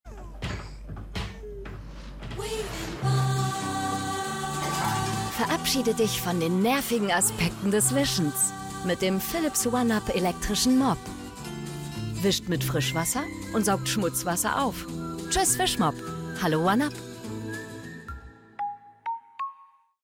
sehr variabel
Mittel plus (35-65)
Norddeutsch
Commercial (Werbung)